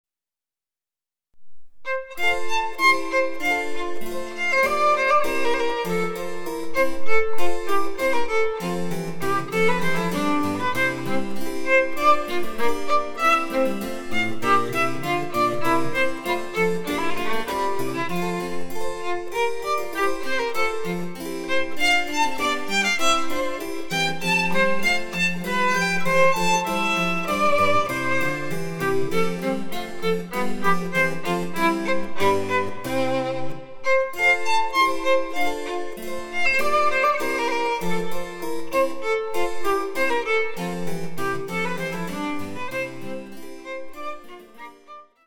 ■ヴァイオリンによる演奏（ヘ長調）